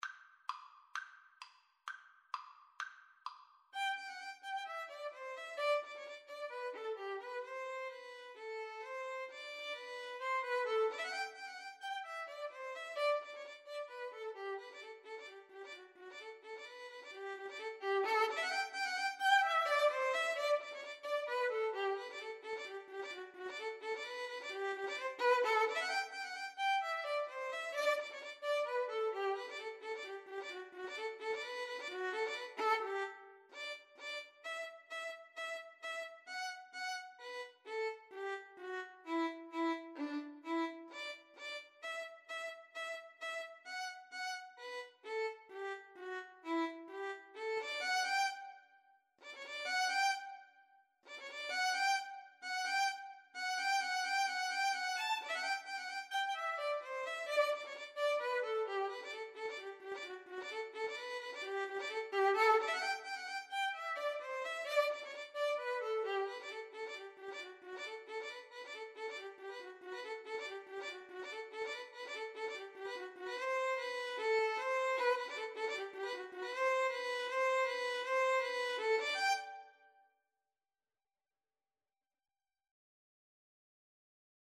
Violin 1Violin 2
G major (Sounding Pitch) (View more G major Music for Violin Duet )
= 130 Tempo di trepak, molto vivace ( = c. 168)
2/4 (View more 2/4 Music)
Classical (View more Classical Violin Duet Music)